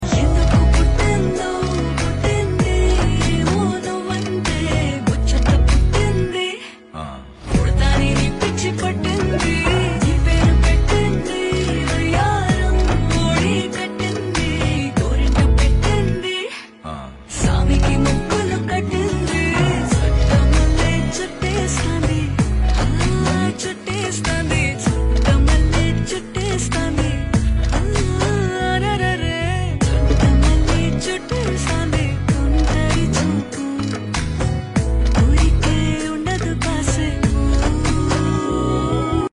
With its catchy melody